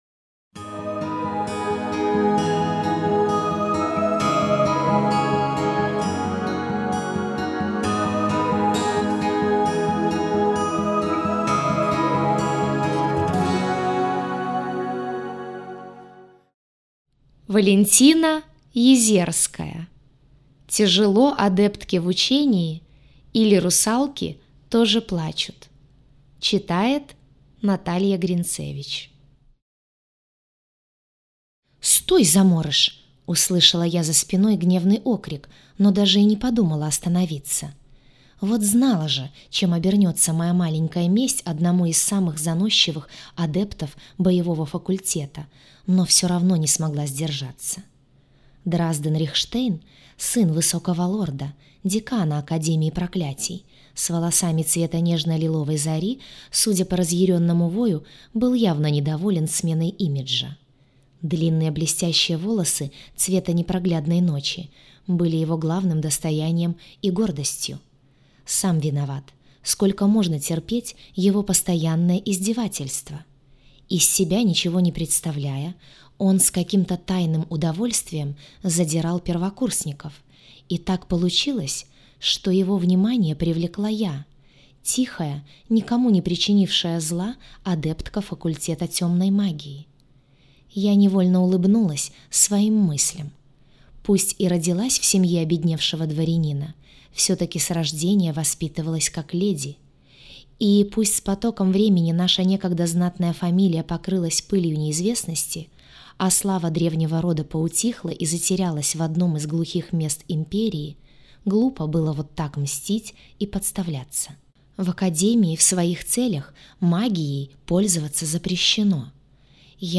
Аудиокнига Тяжело адептке в учении или русалки тоже плачут